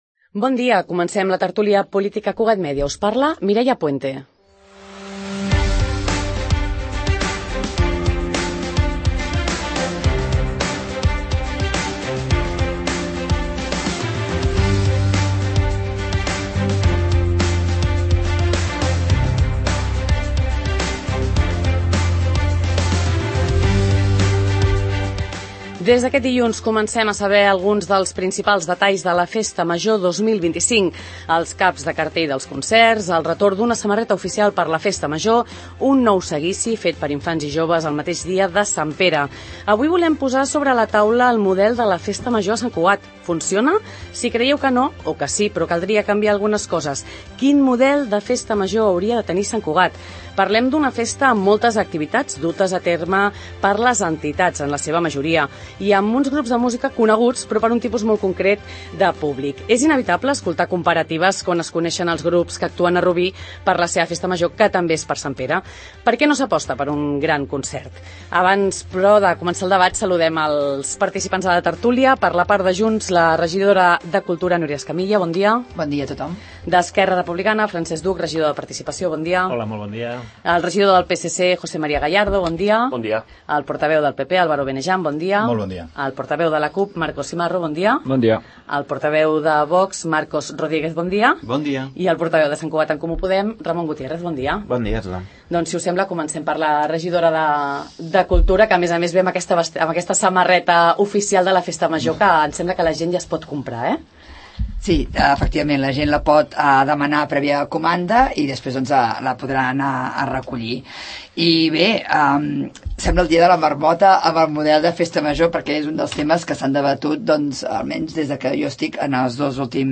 El model de la Festa Major de Sant Cugat, a debat a la tert�lia pol�tica